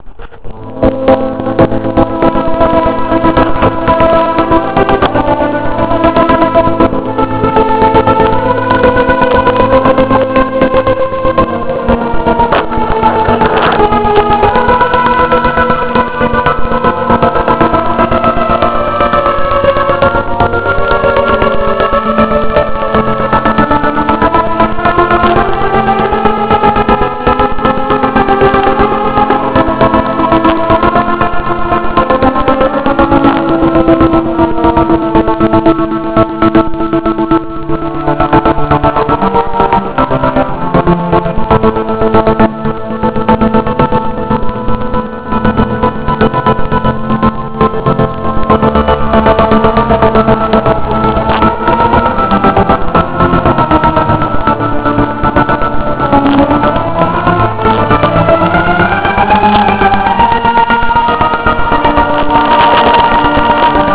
here (I apologize for the poor audio quality — it was a last minute thought to hit “record” on my little MP3 player).
The music was astounding.